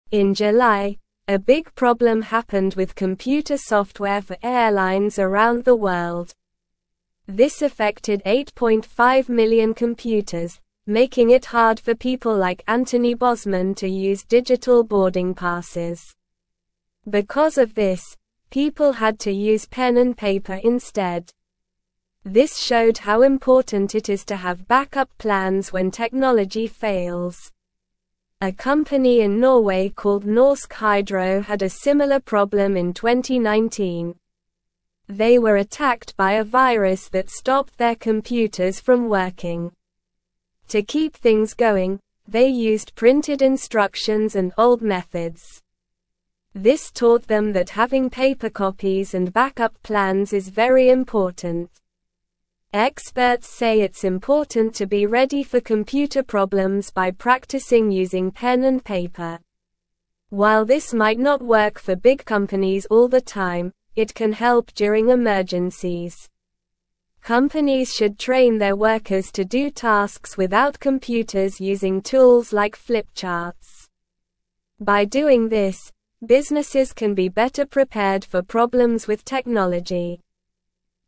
English-Newsroom-Lower-Intermediate-SLOW-Reading-Paper-is-important-when-computers-dont-work.mp3